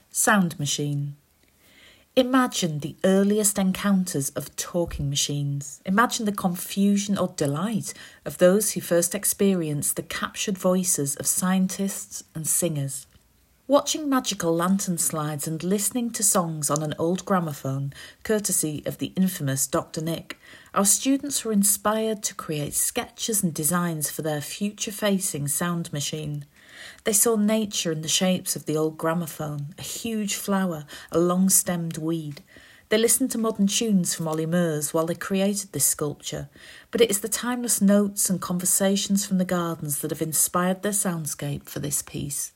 They saw nature in the shapes of the old gramophone: a huge flower, a long stemmed weed.